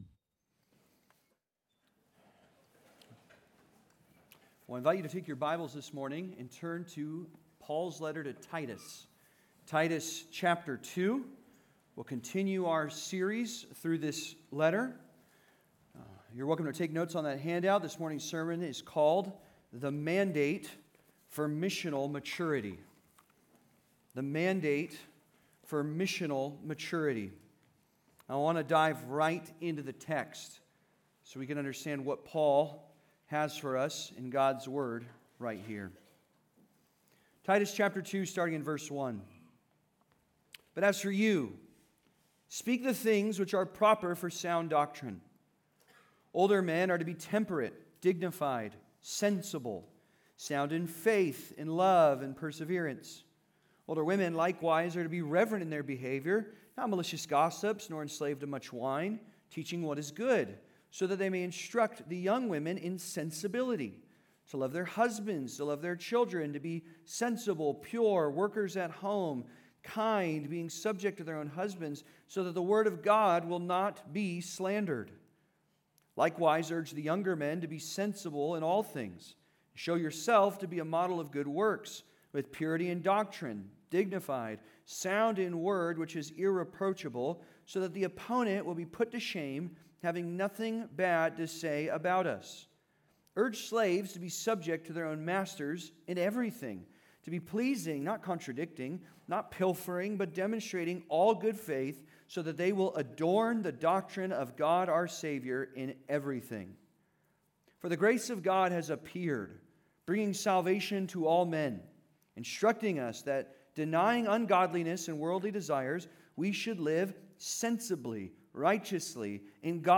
The Mandate for Missional Maturity (Sermon) - Compass Bible Church Long Beach